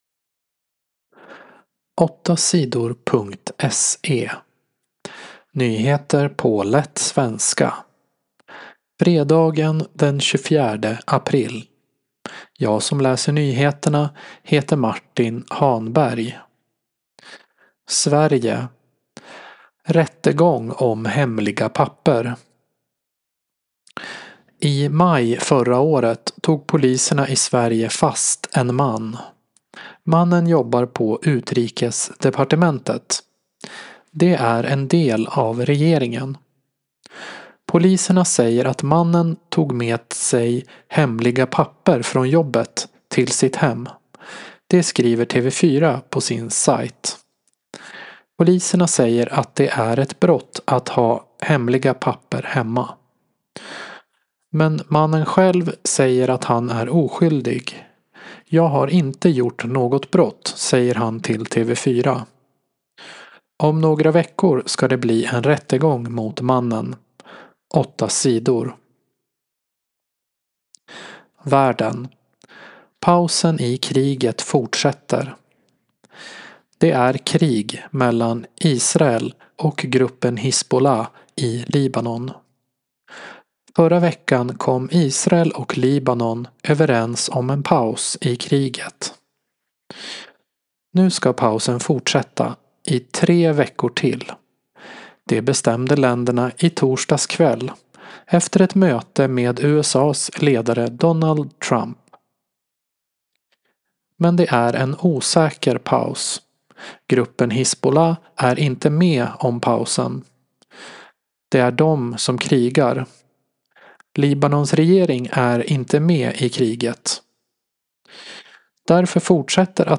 Nyheter på lätt svenska den 24 april